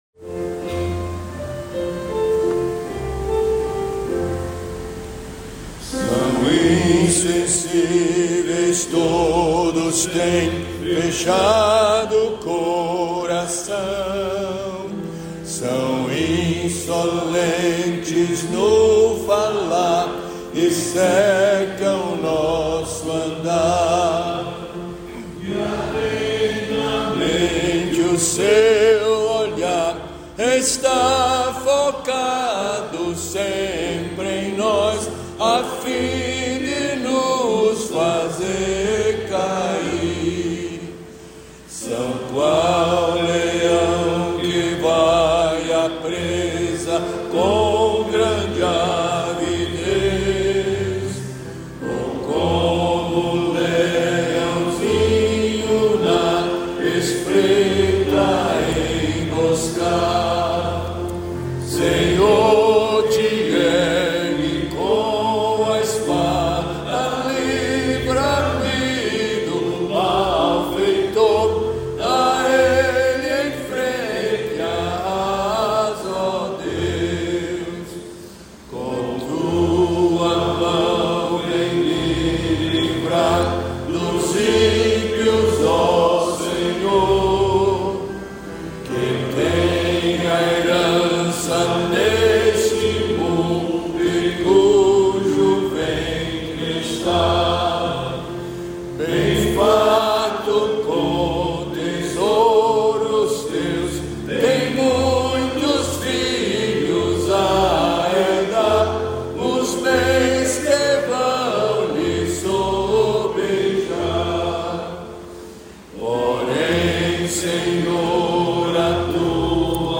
Compositor: Ira David Sankey, 1874
salmo_17B_cantado.mp3